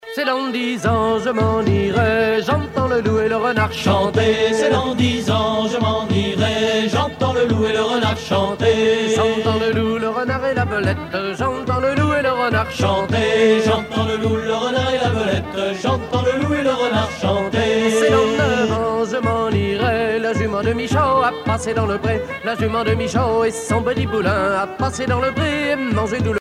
Genre énumérative
Catégorie Pièce musicale éditée